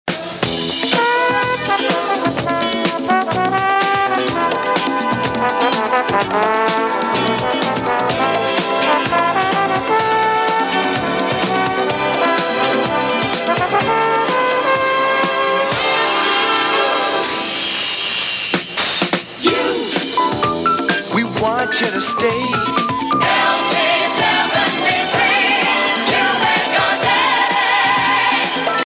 Disco Jingle from 1979